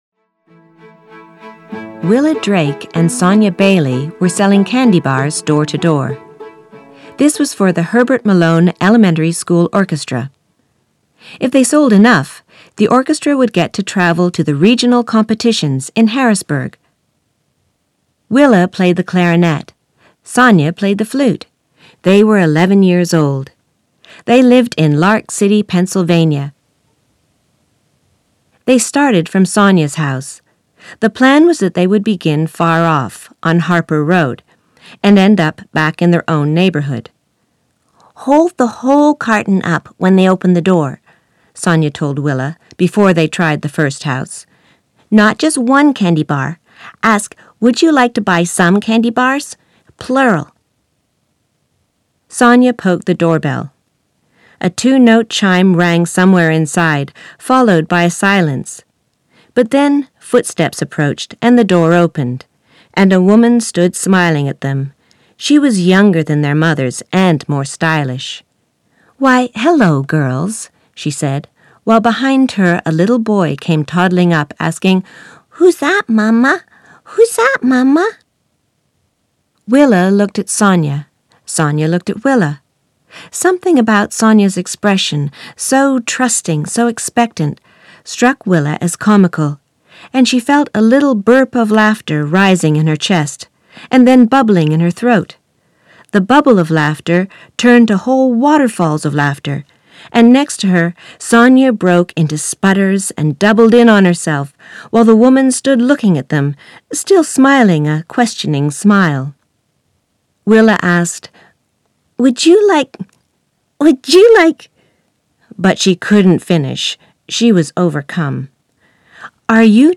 40/50's North American,
Versatile/Reassuring/Natural
Audio Book Showreel